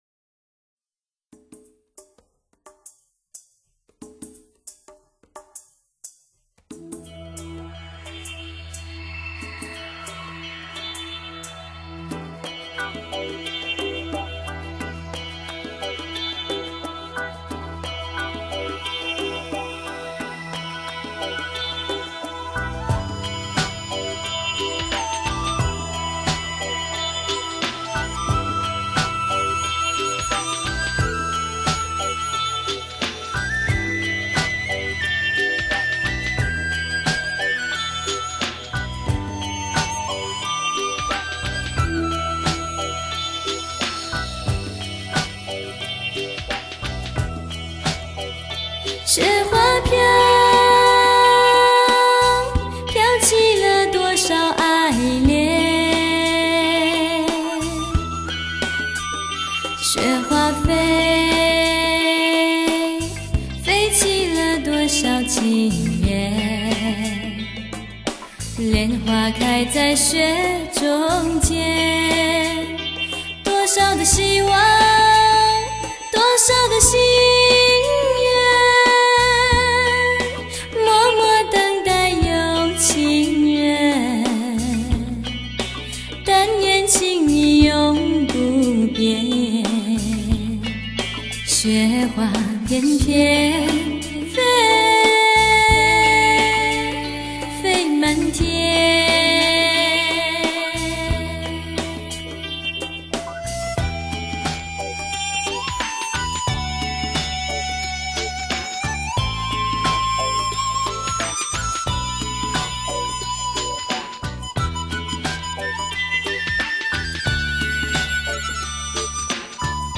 笛子
二胡
吉他